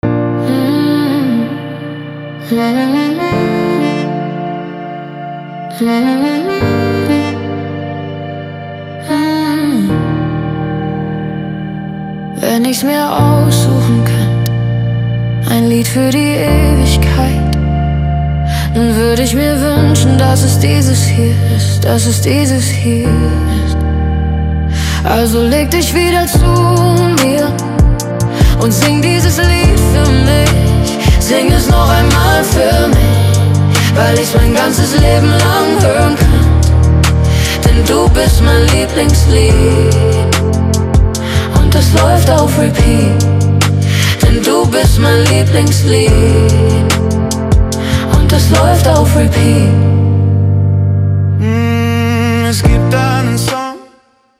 Und wir lieben es, zu zweit zu singen.